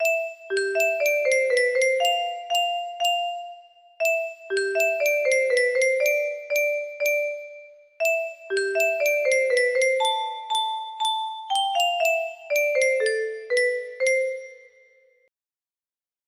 Kikkerland 15 music boxes More